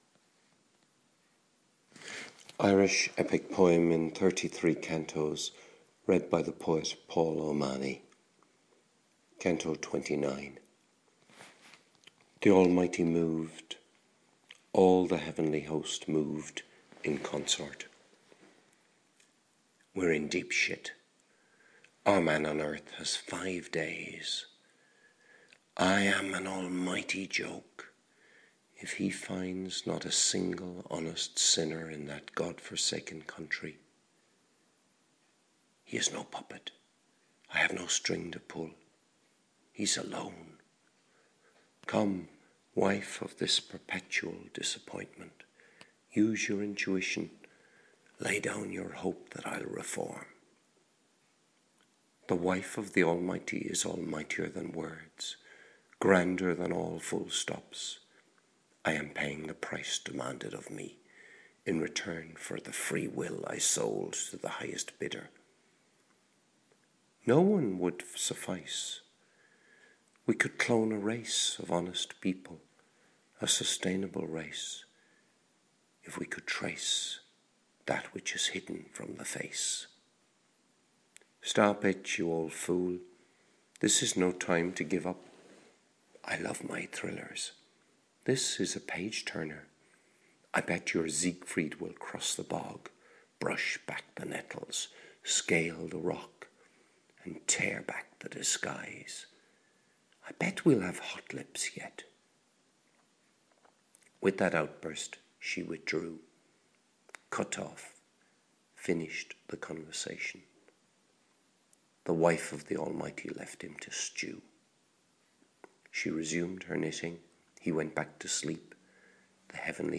Irish Epic Poem in 33 Cantos : Canto 29 - read by poet